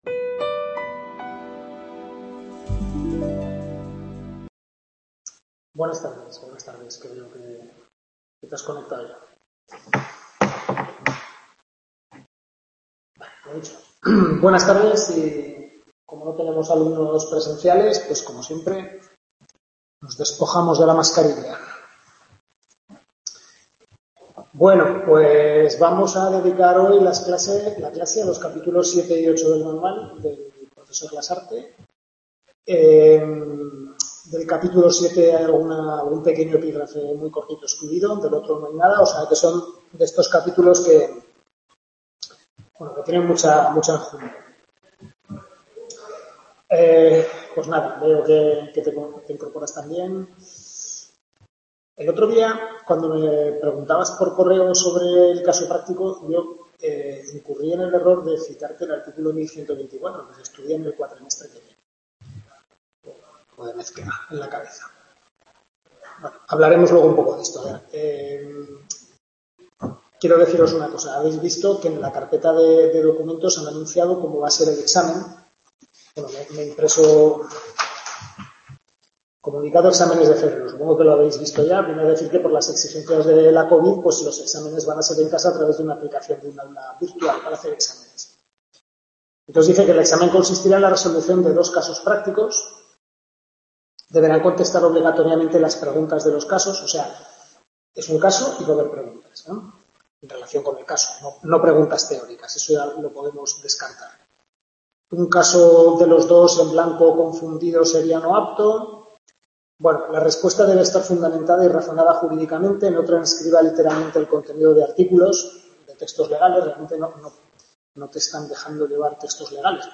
Tutoría de Civil II capítulos 7 y 8 del Manual de Carlos Lasarte